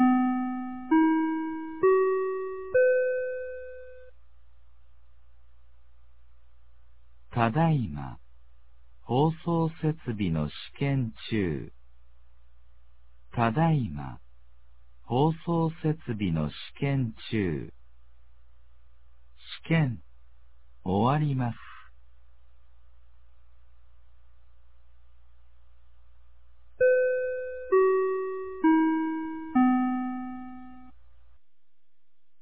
2026年02月28日 16時03分に、美浜町より全地区へ放送がありました。